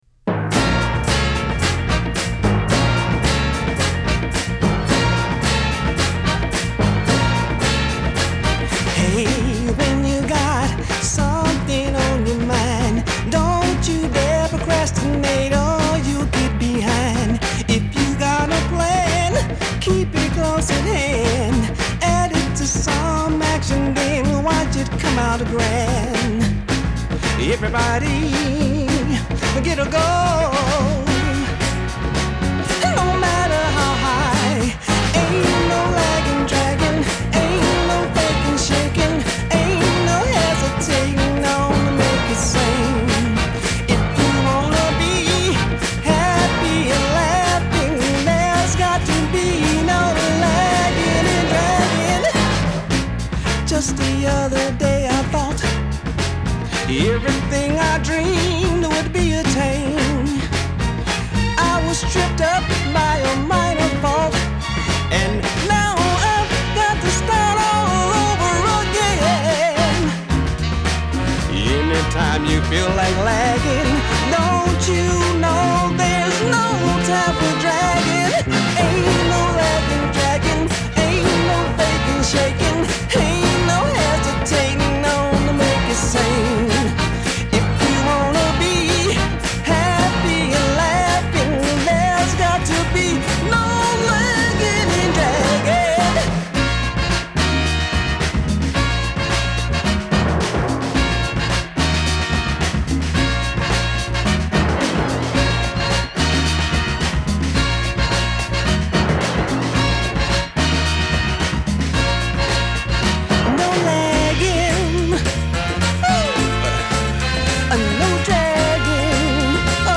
Genre : Funk, Soul